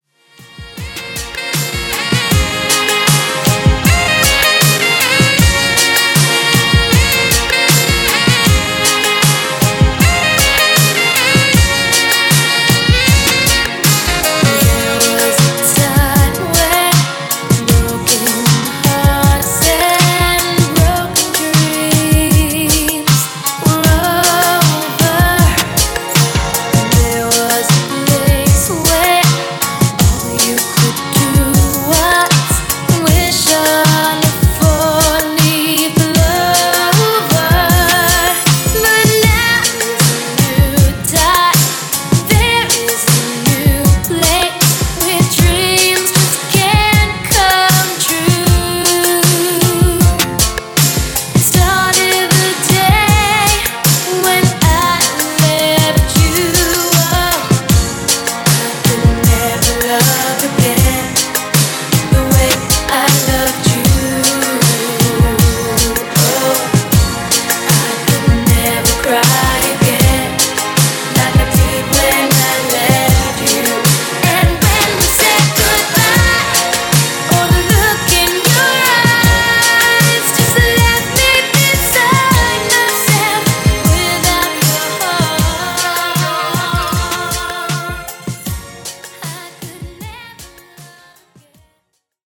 80s Re-Drum)Date Added